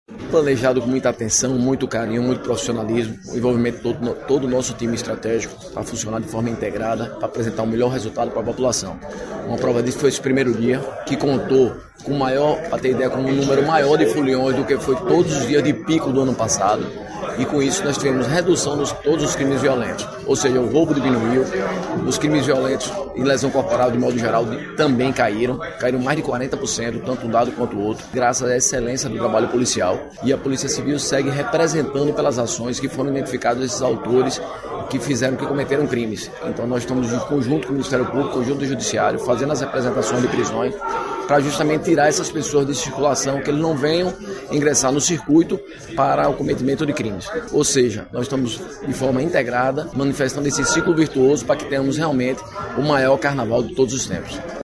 O delegado geral da Polícia Civil, André Viana destaca que o resultado é fruto de um planejamento feito com atenção, profissionalismo e integração entre todas as forças